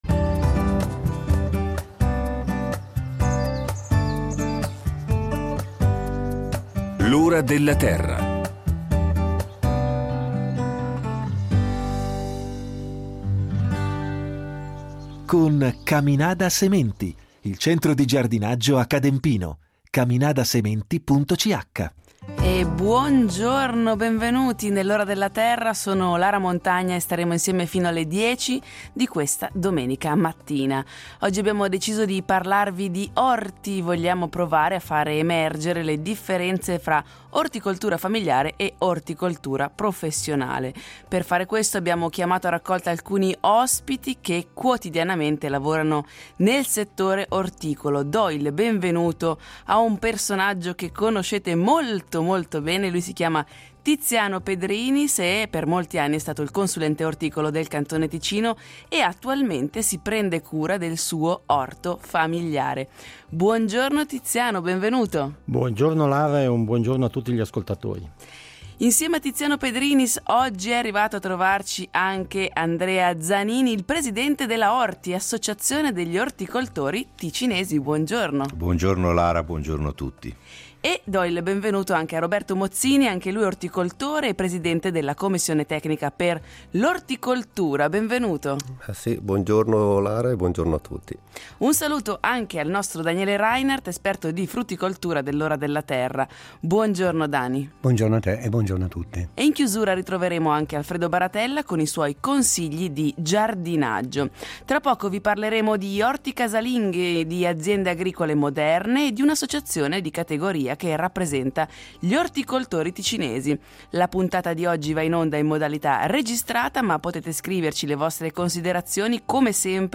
Puntata dedicata all’orto, parleremo delle grandi differenze tra il piccolo orto famigliare e quello dei professionisti. In studio